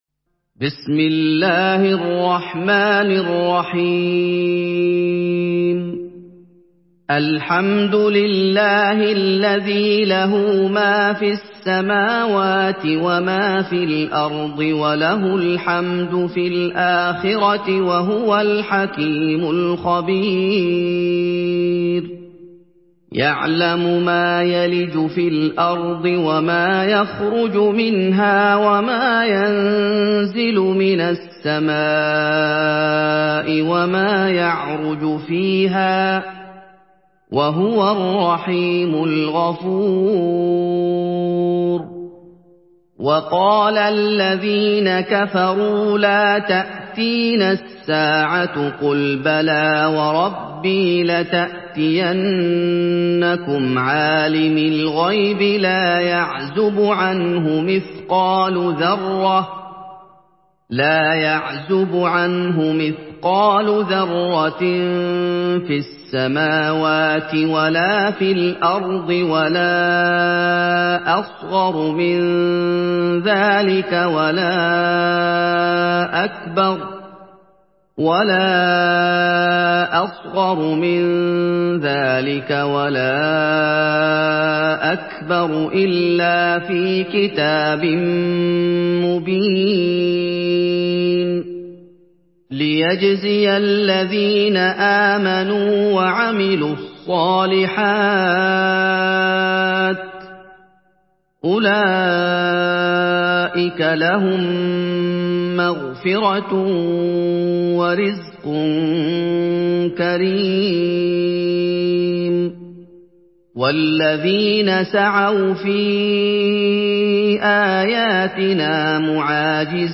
Surah Saba MP3 by Muhammad Ayoub in Hafs An Asim narration.
Murattal